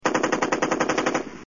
Ratatatatata
Ratatatatata.mp3